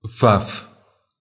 Fafe (Portuguese pronunciation: [ˈfafɨ]
Pt-pt_Fafe_FF.ogg.mp3